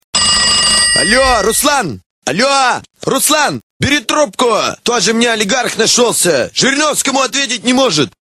Категория: Рингтоны пародии